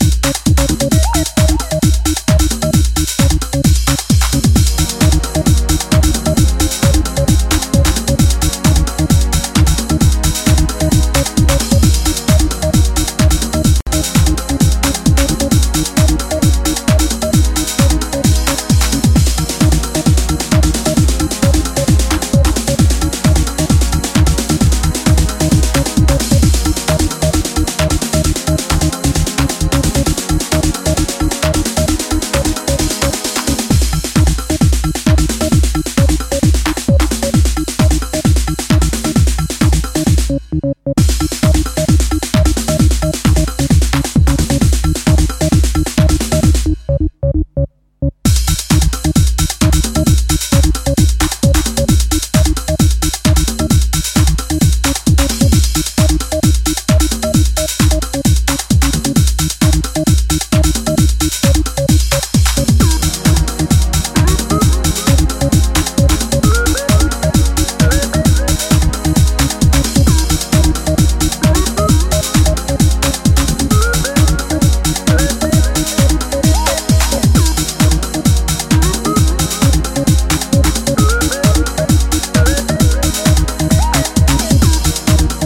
Remastered